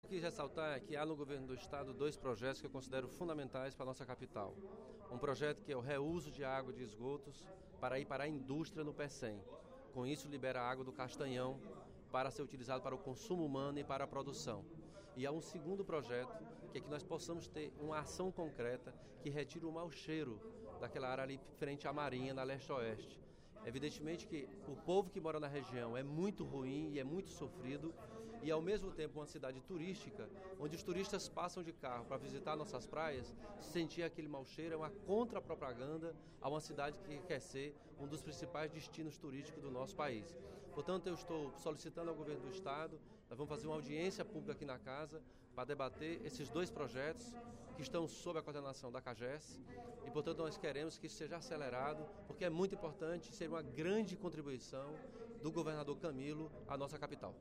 O deputado Elmano Freitas (PT) comentou a aprovação, pelo Senado Federal, da medida provisória (MP) nº 668, durante o primeiro expediente da sessão plenária desta quarta-feira (03/06). A matéria inclui 74 municípios na região semiárida brasileira.